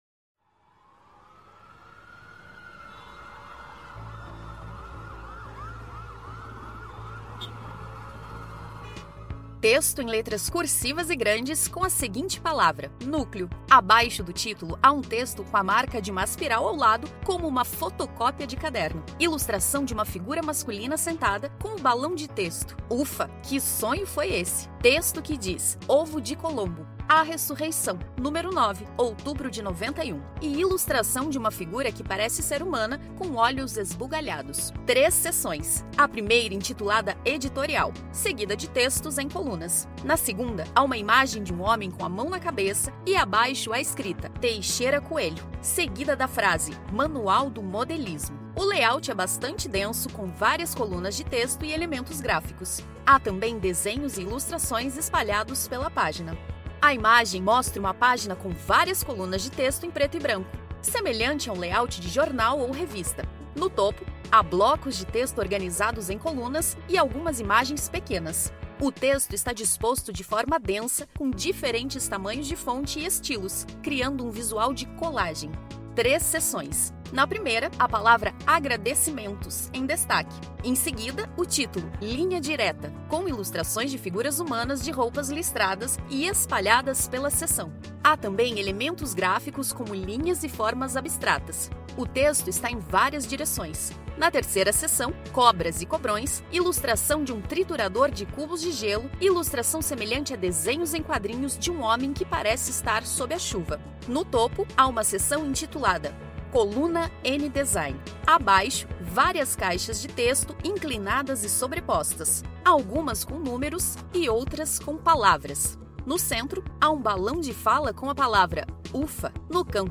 Audiodescrição do Fanzine n° 9